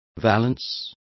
Complete with pronunciation of the translation of valence.